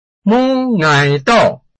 臺灣客語拼音學習網-客語聽讀拼-饒平腔-開尾韻
拼音查詢：【饒平腔】ngai ~請點選不同聲調拼音聽聽看!(例字漢字部分屬參考性質)